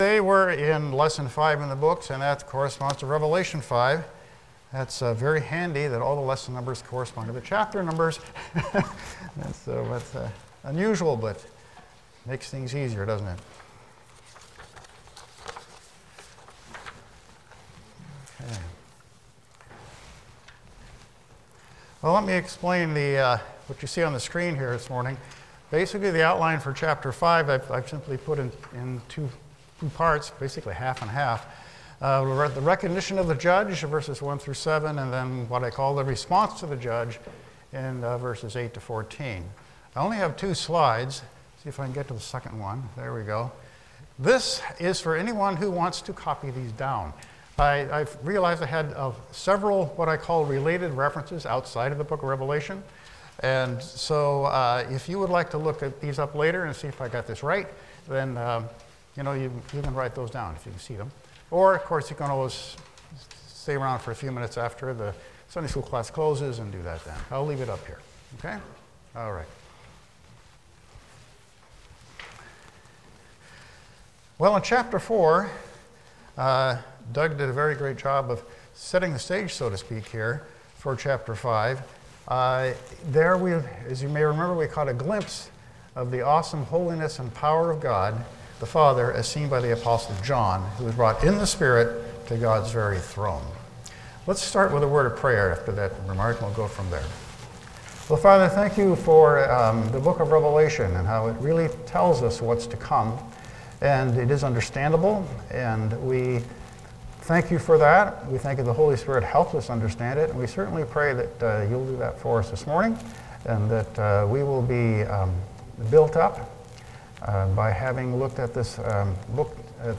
Passage: Revelation 5 Service Type: Sunday School